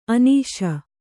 ♪ anīśa